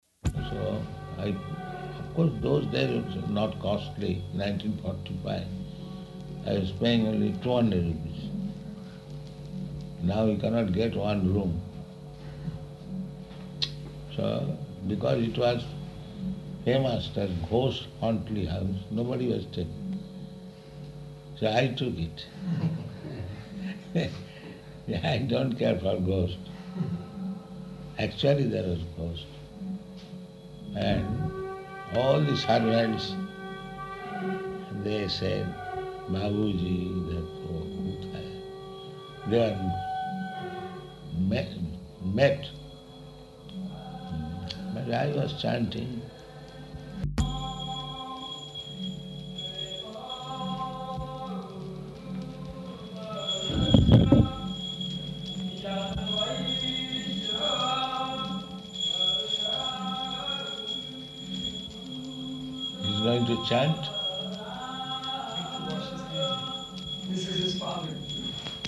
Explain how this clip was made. Location: Māyāpur